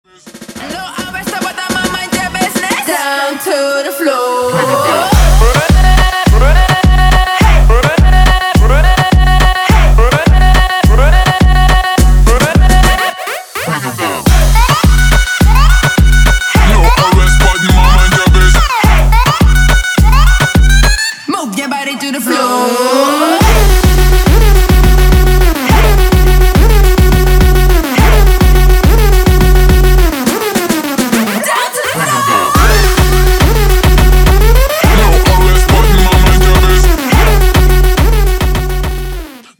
• Качество: 320, Stereo
house